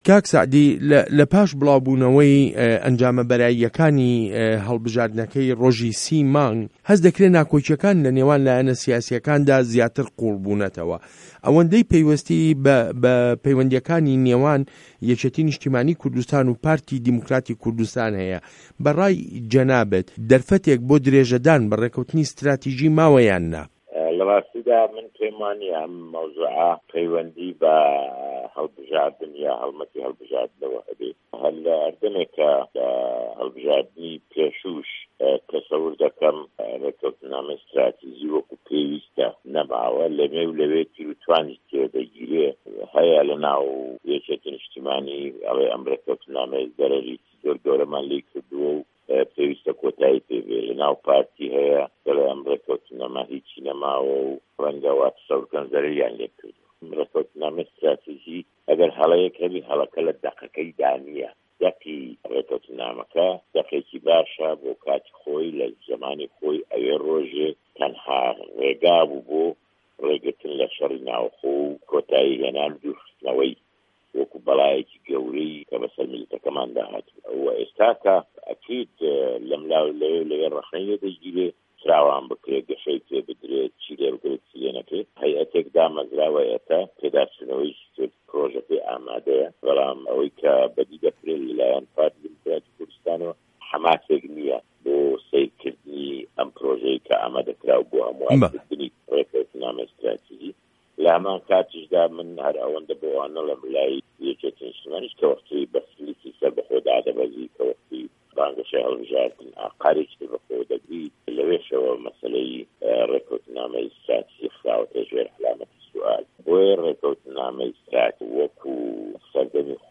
وتووێژ له‌گه‌ڵ سه‌عدی ئه‌حمه‌د پیره‌